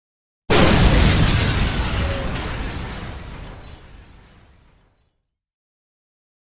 Explosion Heavy Glass Destruction Type 1 Version 2